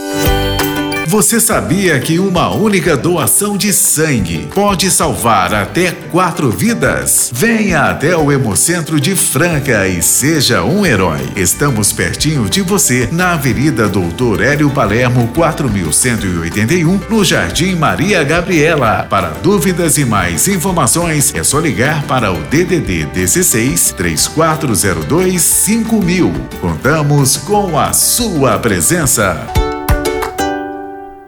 Você também pode ajudar a divulgar a doação de sangue usando nossos spots para rádio ou carros de som: